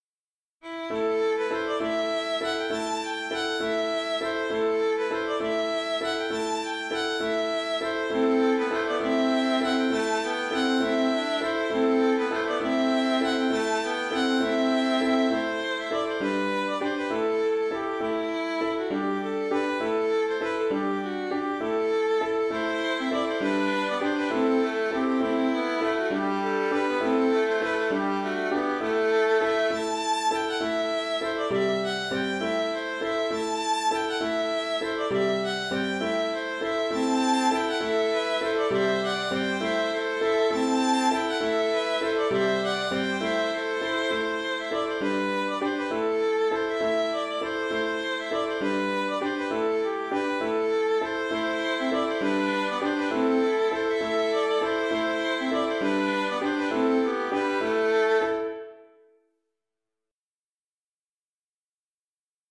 Bourrée dorienne 2 (Bourrée 3 temps) - Compositions